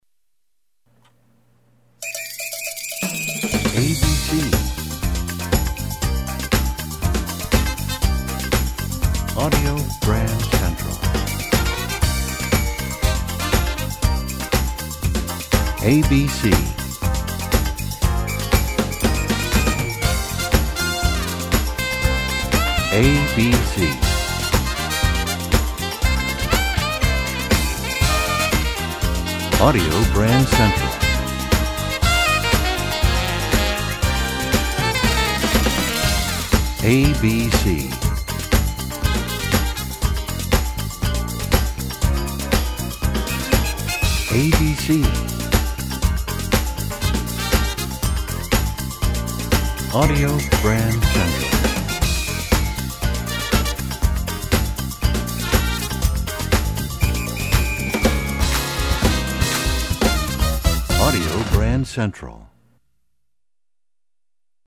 Genre: Theme Music.